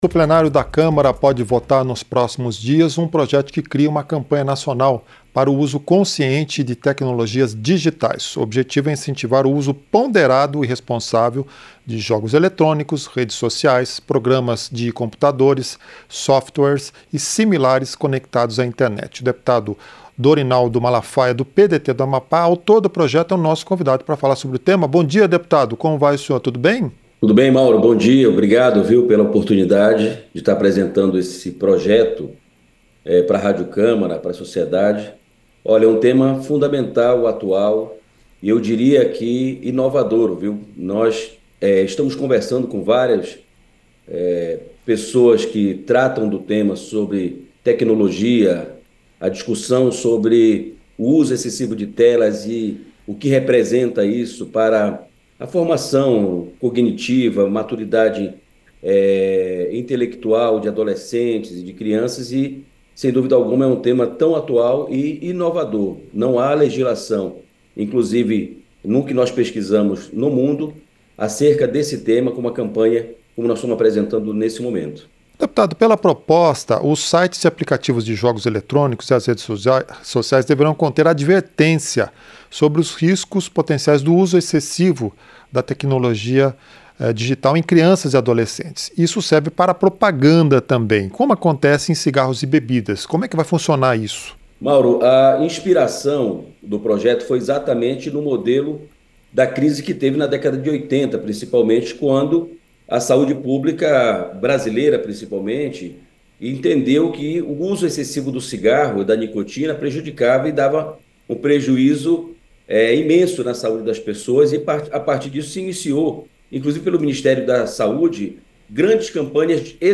Entrevista - Dep. Dorinaldo Malafaia (PDT-AP)